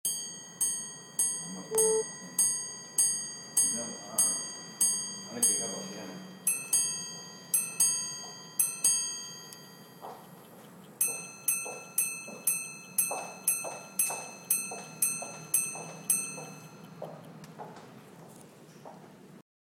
[分享]江詩丹頓Ref.80172三問陀飛輪萬年曆腕錶問錶聲音(已修正)
現場實錄, 略有雜音, 請見諒
聲音還滿清晰的